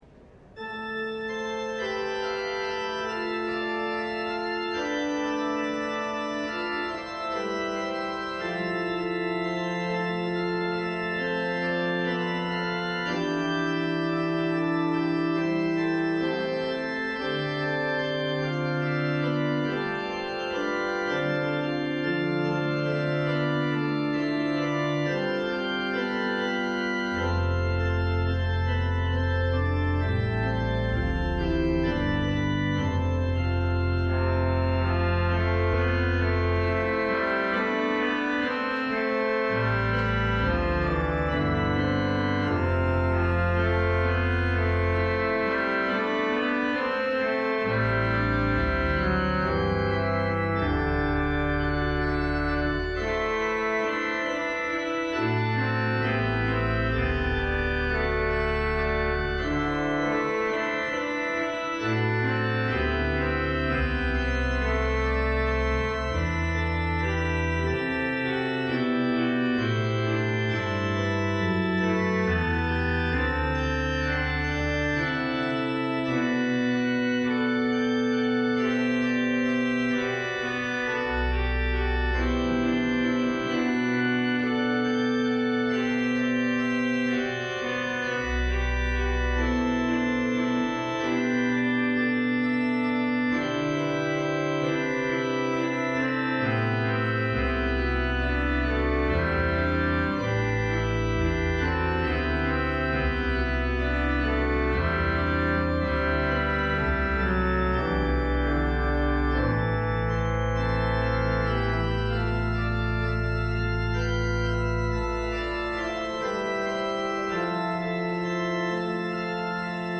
LIVE Morning Service - The Word in the Windows